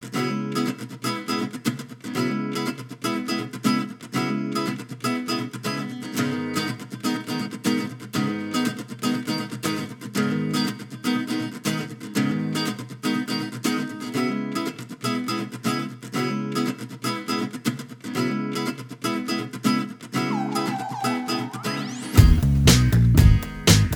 Minus All Guitars Pop (2010s) 3:57 Buy £1.50